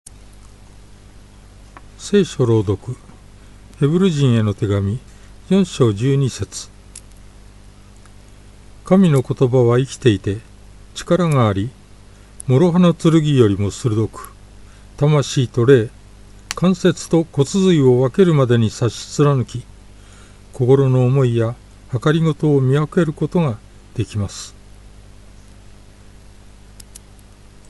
BibleReading_Heb4.12.mp3